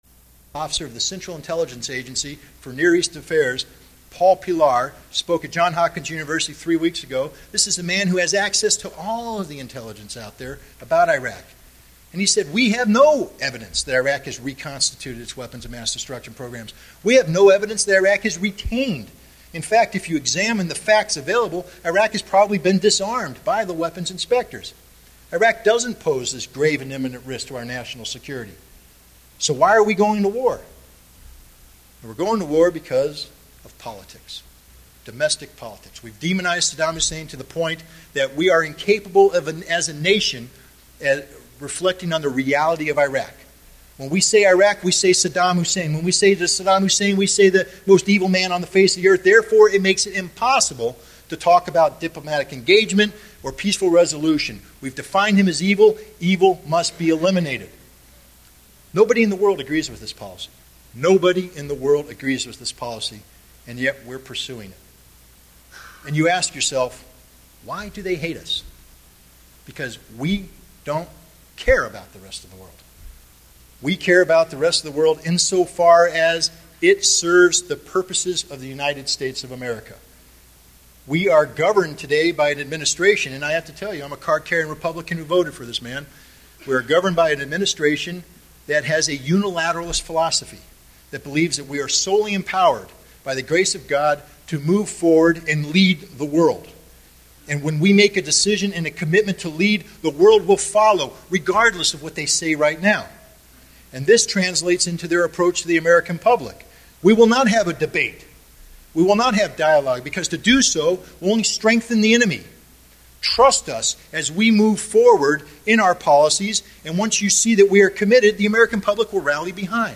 Admidst recent hawkish cries from Washington to expand the War on Terrorism to an attack on Iraq, fomer UN Weapons Inspector, Scott Ritter offers a candid, critical, perspective of US Foreign Policy and the "War on Terrorism." Audio: 3 minute segment (full speech is also online) (article 1)